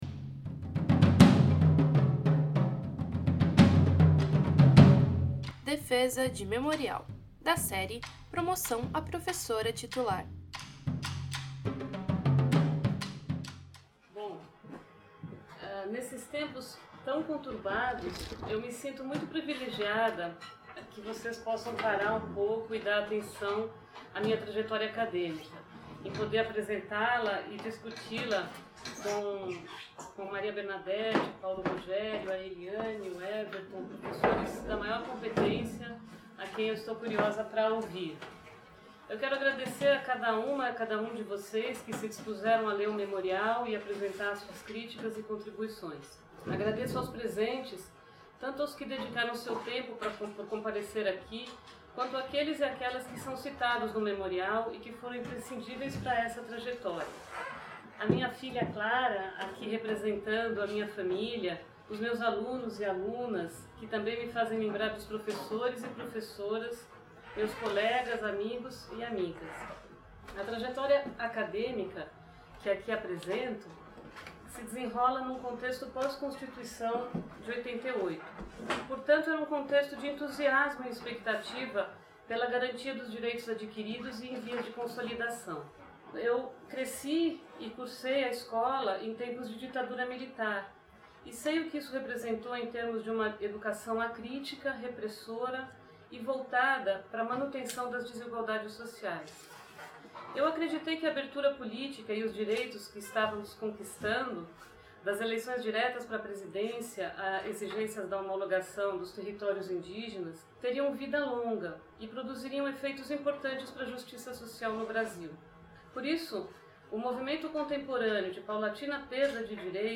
no Auditório do MArquE